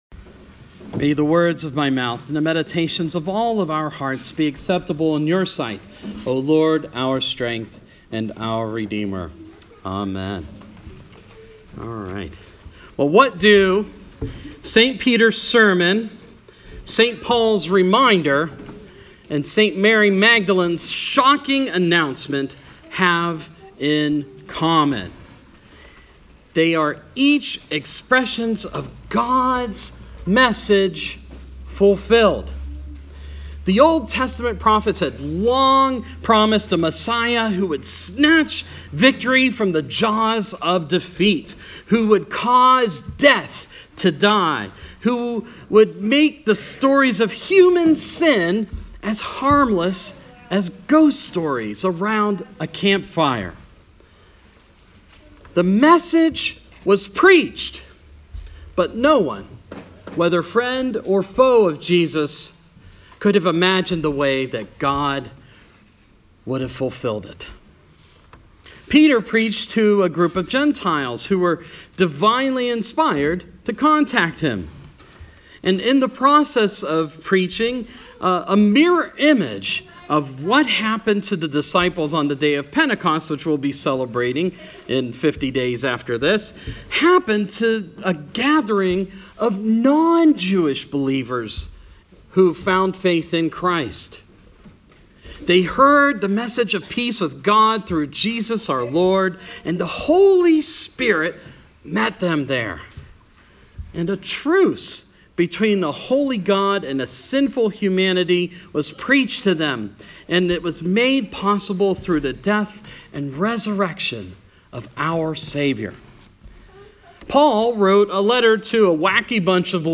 Sermon
& Easter Baptism (St Francis)